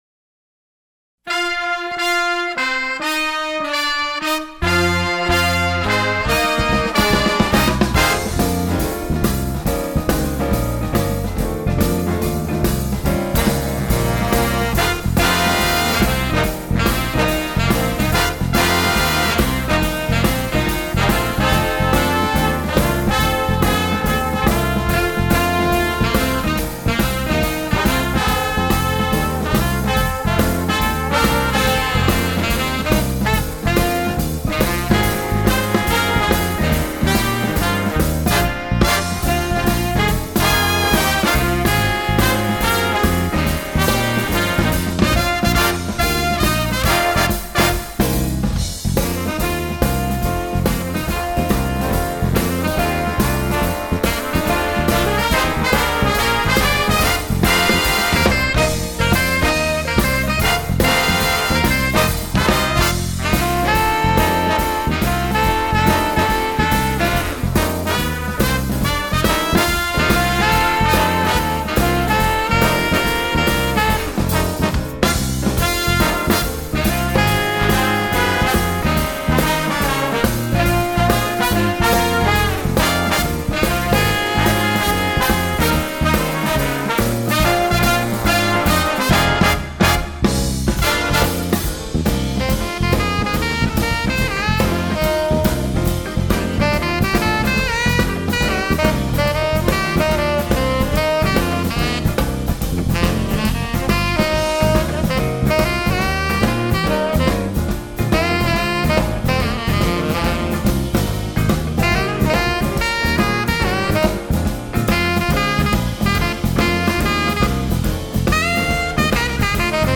jazz, rock